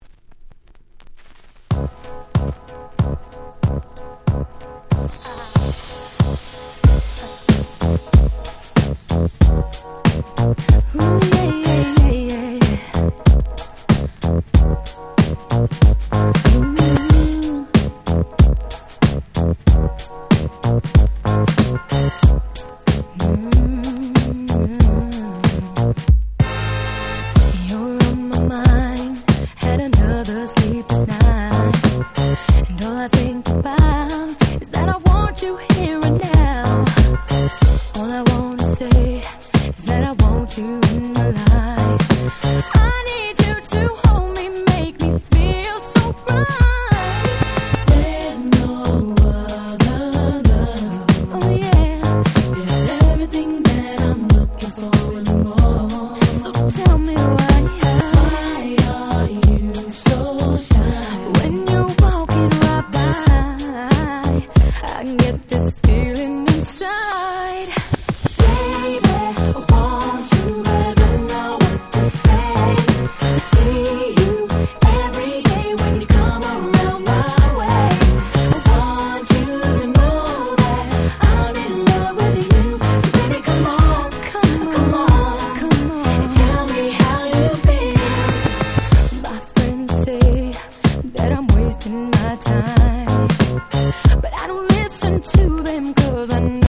00' Very Nice R&B !!
キャッチー系